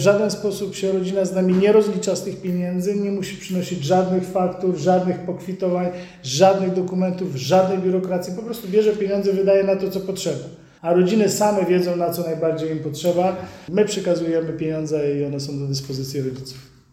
– mówi burmistrz Czapla.